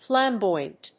音節flam・boy・ant 発音記号・読み方
/flæmbˈɔɪənt(米国英語)/